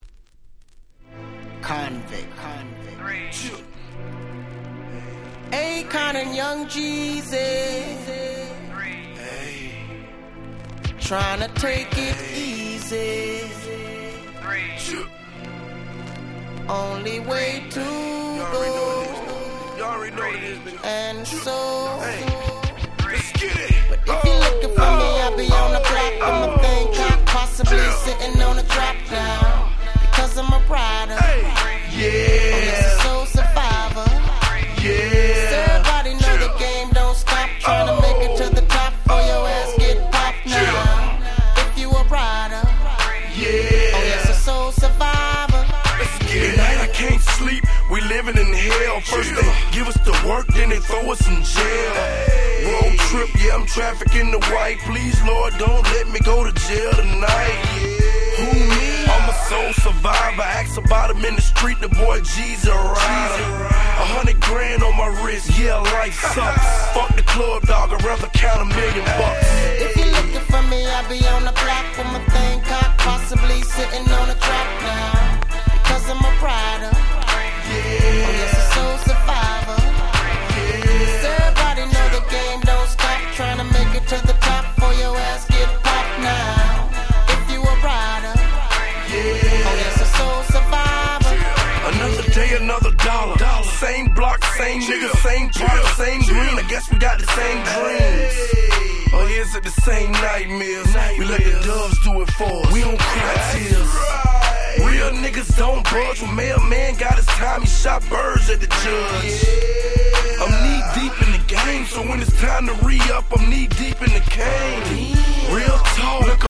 小傷によるプチノイズ箇所あり。)
05' Super Hit Hip Hop !!